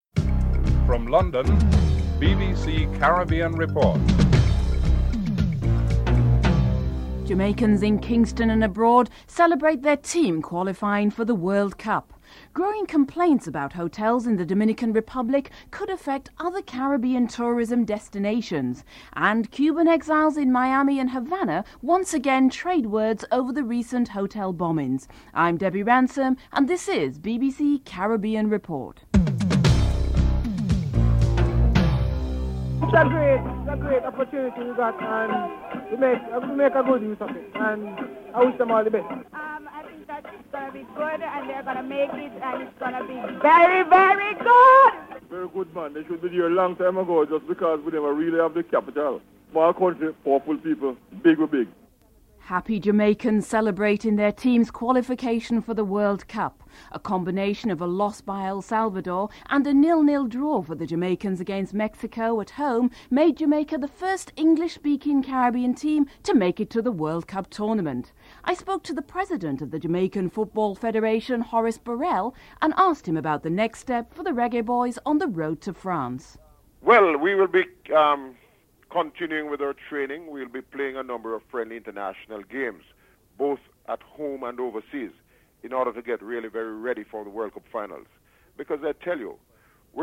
Headlines (00:00-00:31)